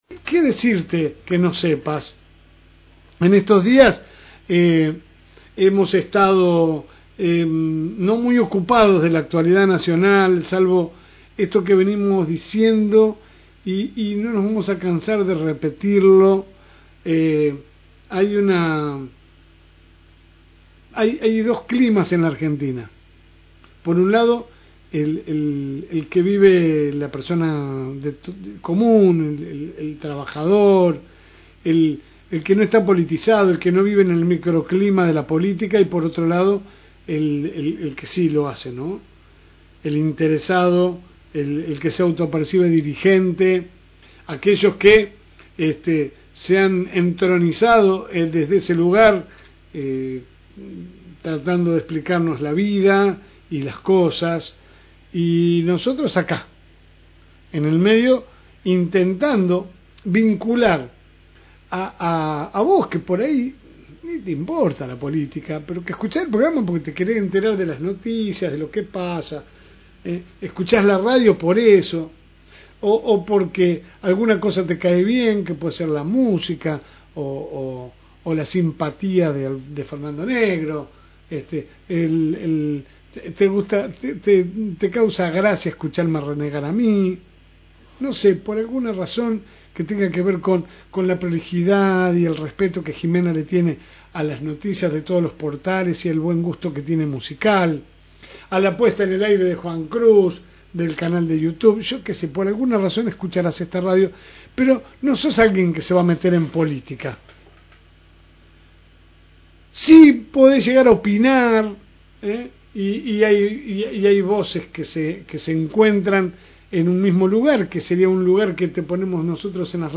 AUDIO – Editorial – FM Reencuentro
Compartimos la editorial del día.